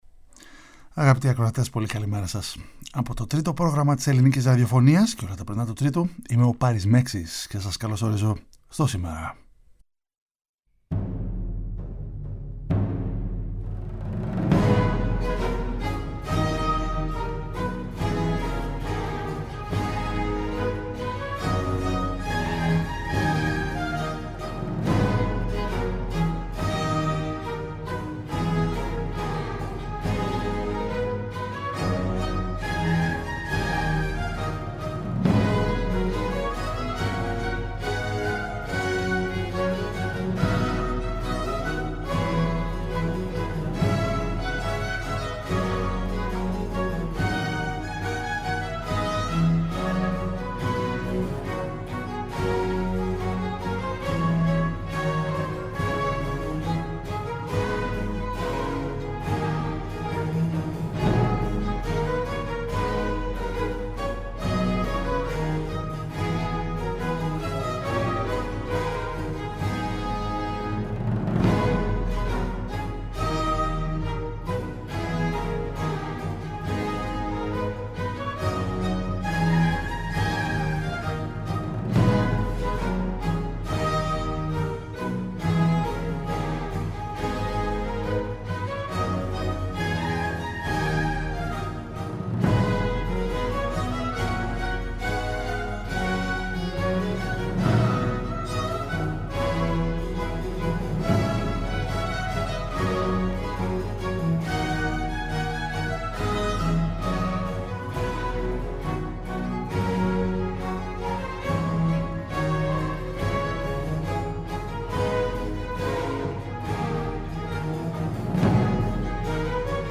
με χιούμορ και θετική διάθεση, μουσική από όλο το φάσμα της ανθρώπινης δημιουργίας δίνοντας έμφαση στους δημιουργούς